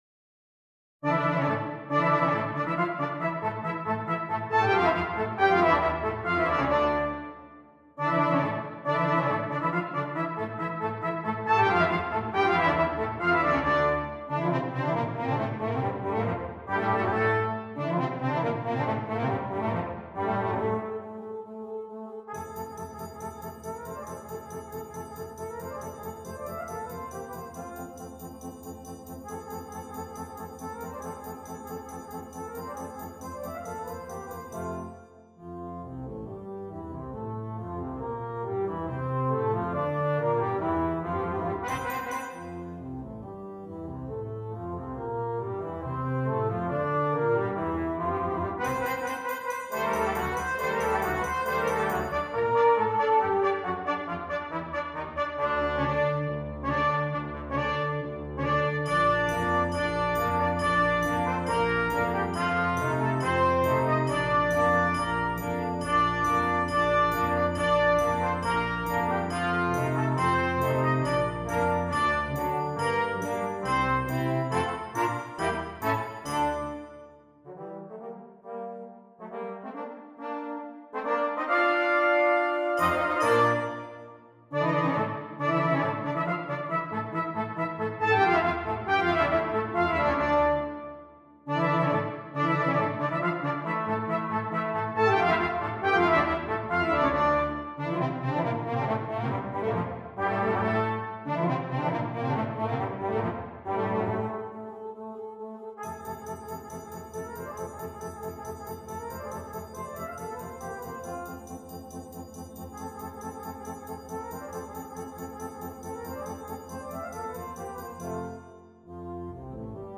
Brass Quintet
This is a flashy piece and everyone gets a good part.